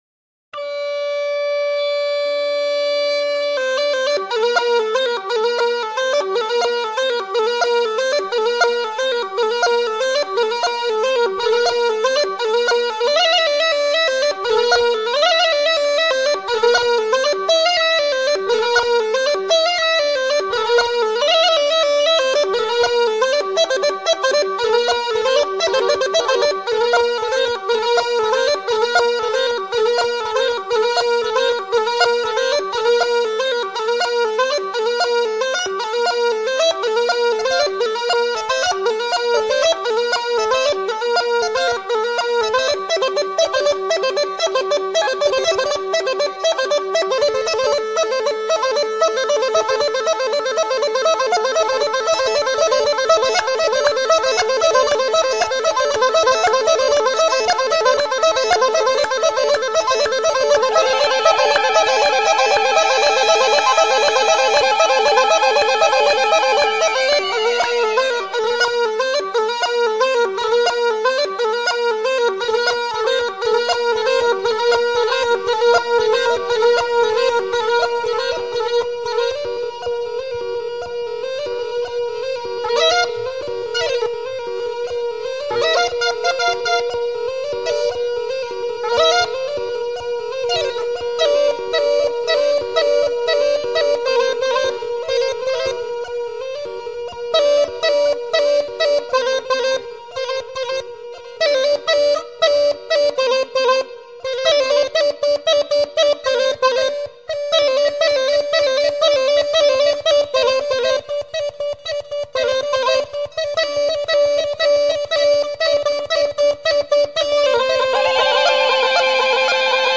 ریتم بندری
تمپو ۱۱۸ دانلود